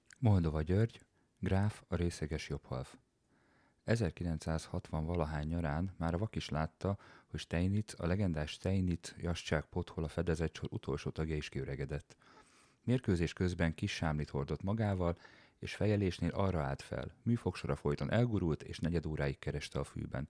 Szépen, tisztán szól. 🙂